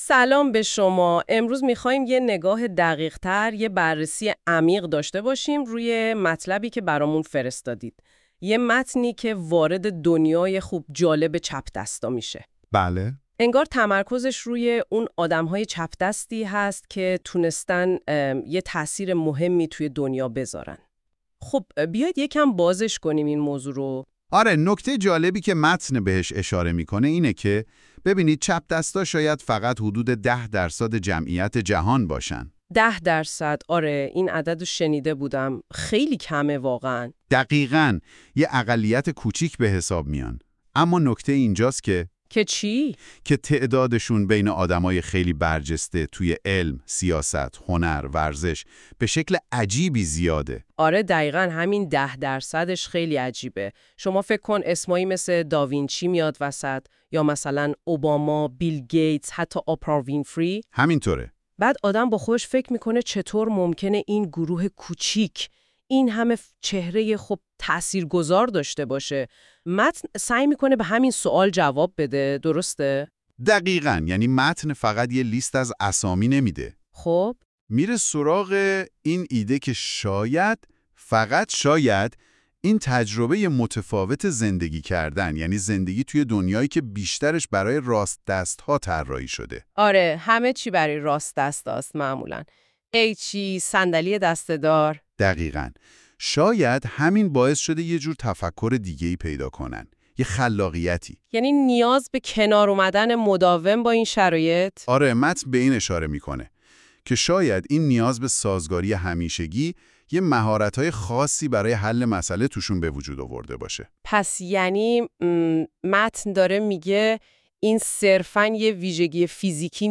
در پادکستی که توسط هوش مصنوعی تولید شده و هم اکنون میشنوید, درباره ی تاثیر گذاری چپ دست ها در تاریخ فرهنگ و هنر و نیز سیاست صحبت شده است.